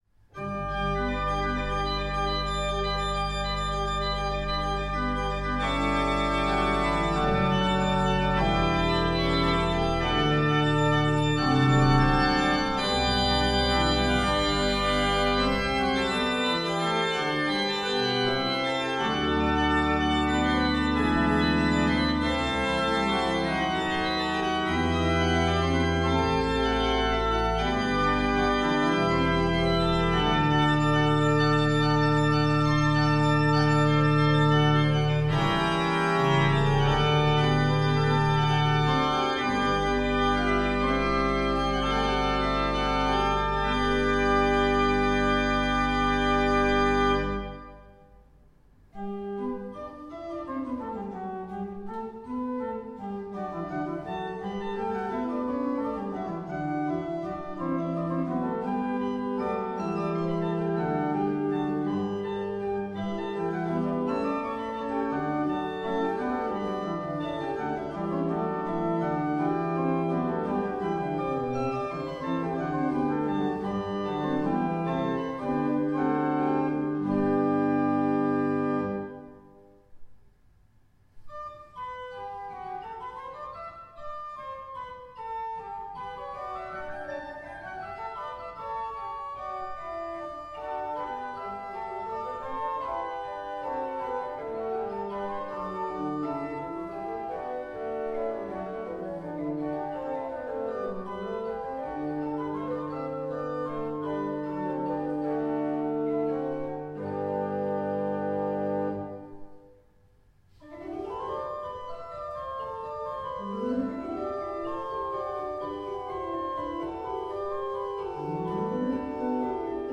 Asch, NAK, HEINTZ-Orgel
Neuapostolische Kirche, 89143 Asch
HEINTZ ca. 1970 II/P 8
Mechanische Schleiflade. Angebauter Spieltisch.
Bourdon 8' Salicional 8' Subbaß 16'